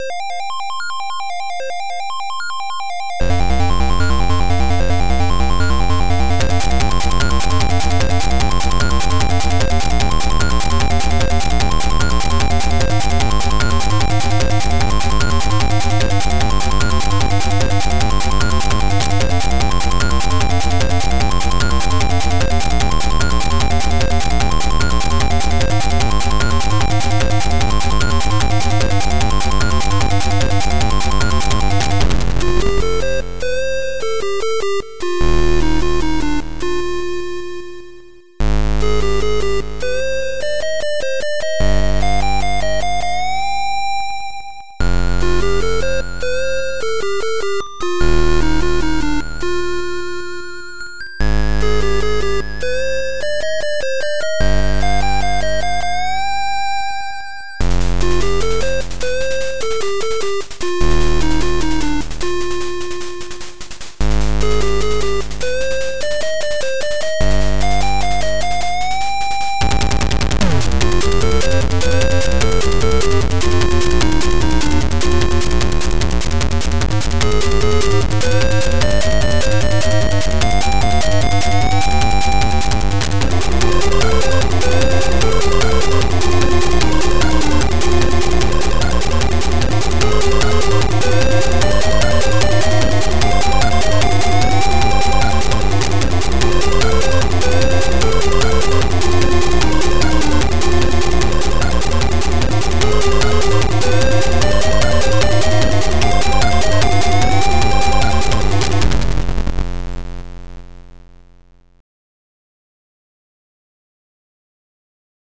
ZX Spectrum + AY
• Sound chip AY-3-8912 / YM2149